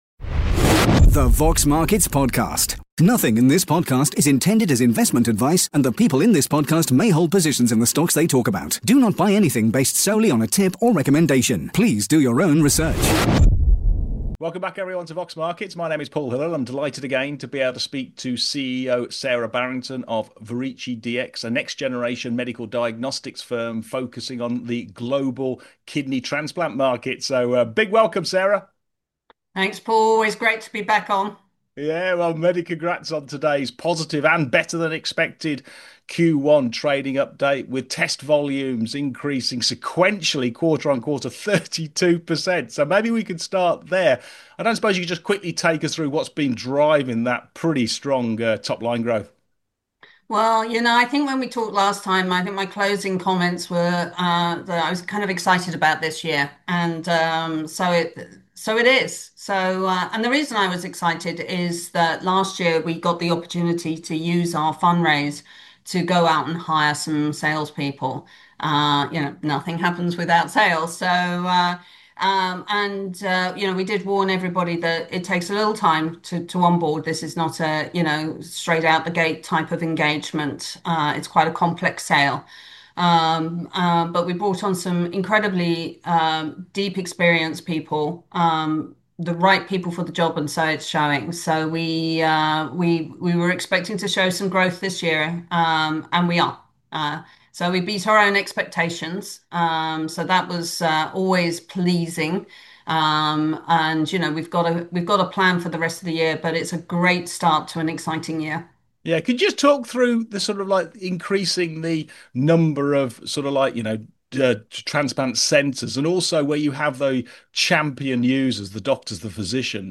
In this upbeat interview